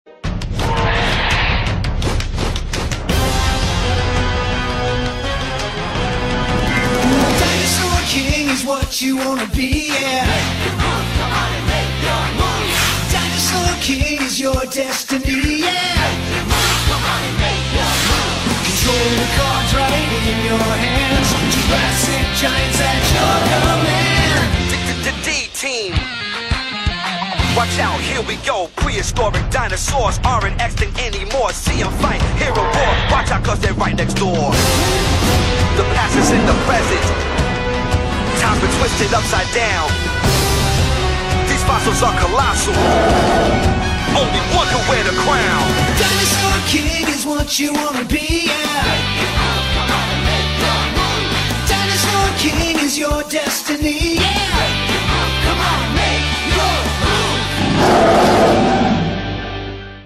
ملك الديناصورات - الحلقة 1 مدبلجة